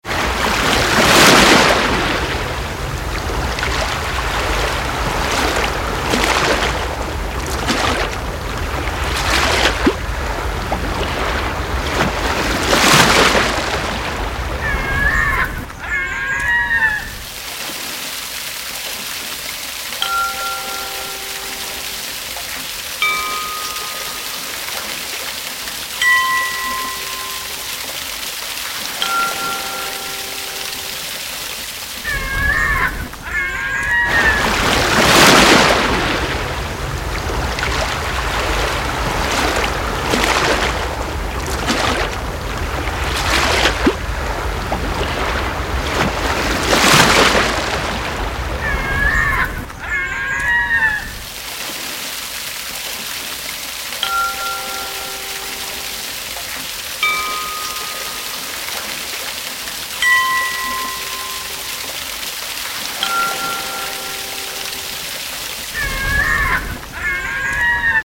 1: Waves splashing onto a beach
2: 2 Seagull cries
3: Sounds like rain but is supposed to be wind in a pine forest with a wind chime at 3 second intervals !!
By the way, all sounds used here are from a sound library site and are all royalty free, if you want me to do something for you, you must supply samples that are royalty free too.
I found some better wind chime sounds, so I've done a quick edit this morning with the new  chimes .. sounds pretty good now though I say it myself.